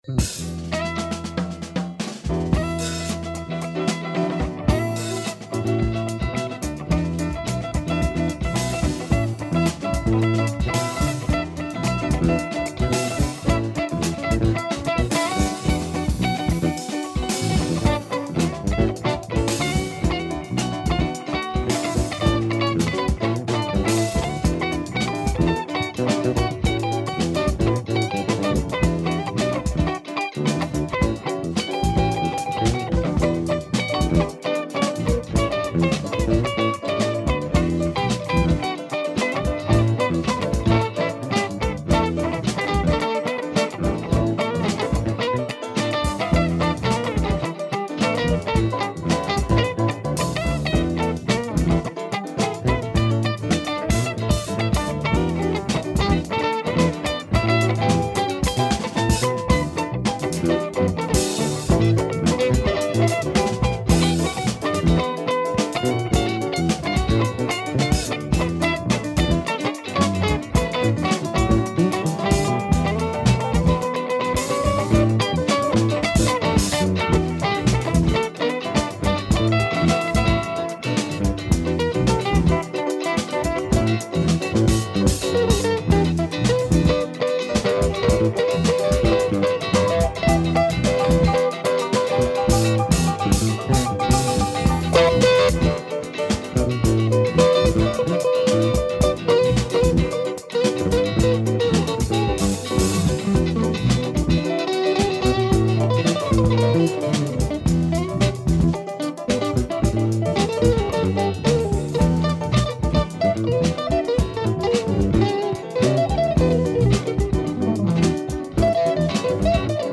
prog jazz